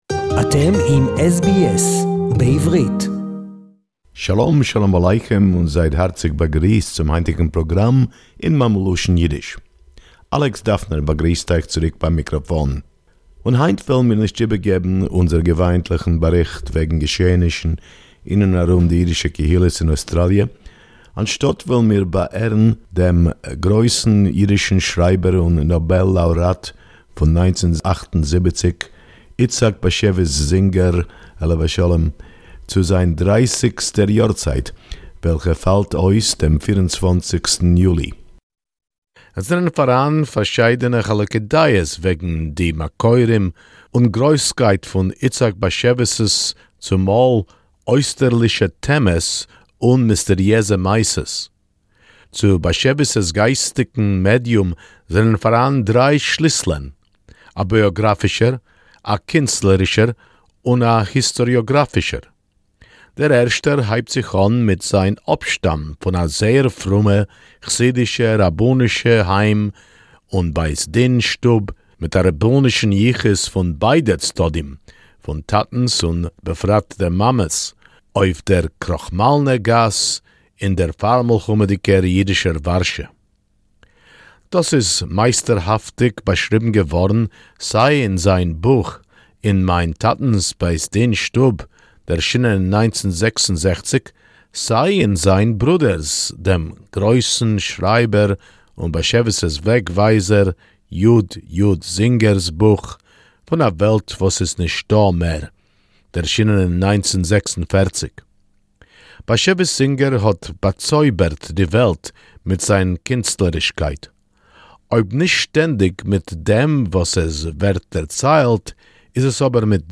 In honour of the 30th yortzayt-death anniversary of Itzhak Bashevis Singer, e”h, renowned Yiddish writer and Nobel Prize Laurate for Literature 1978, commemorated on the 24th July 2021, we hear a little about his life, his creativity and Singer himself speak about his motivations and influences as a Jewish, Yiddish writer.